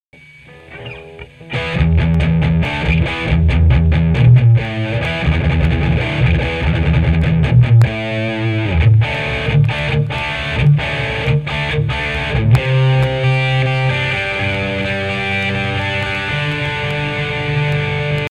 Gitara UPG, snimac KA PAF. V kazdom preste som vypol reverb, nech je menej pukancov, mam slaby pocitac, nie?
Sumu je tam neurekom, aj preto hravam cca na 1/3 gaine ako si mal nastaveny ty.
Mp3 su stereo, pre tvoje pohodlie.
okrem toho ti to este pulzuje hlasitostne - tvrdo to limutuje, co znamena ze mas prevaleny este aj output